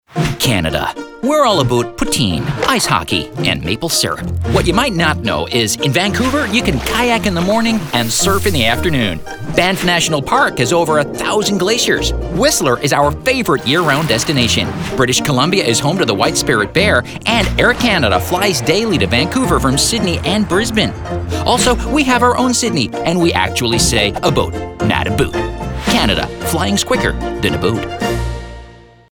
Radio Commercials
(Canadian Accent)